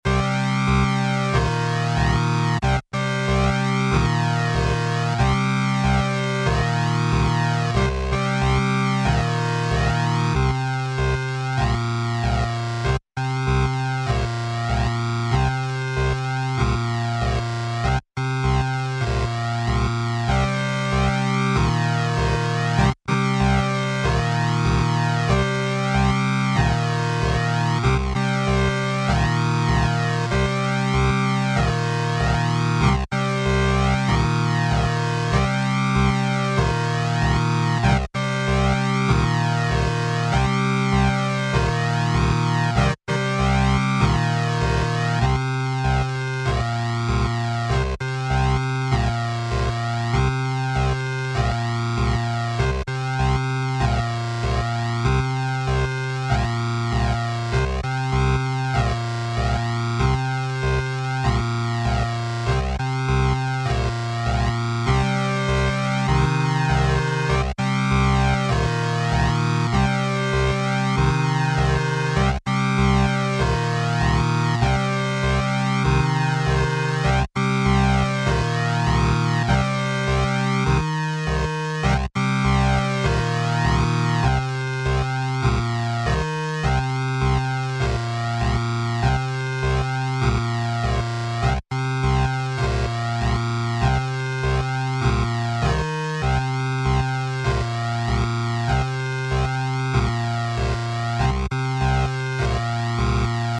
8 bit Gaming Musik
Tempo: langsam / Datum: 15.08.2019